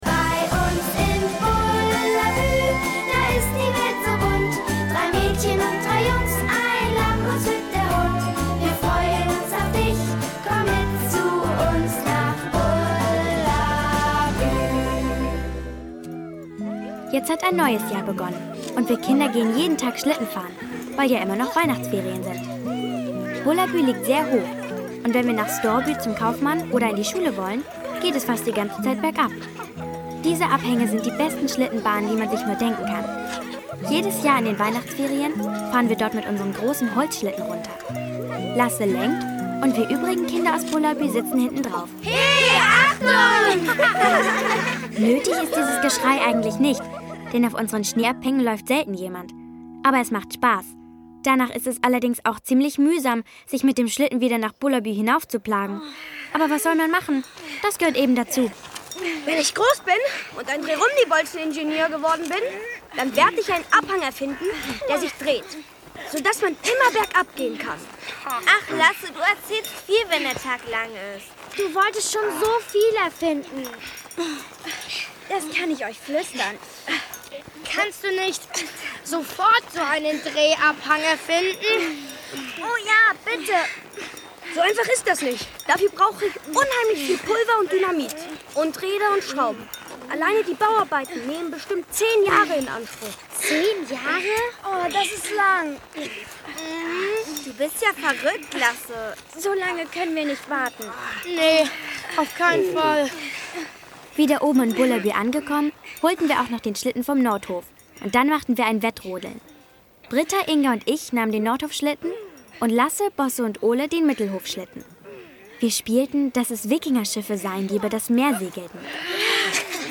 Wir Kinder aus Bullerbü 2. Mehr von uns Kindern aus Bullerbü. Das Hörspiel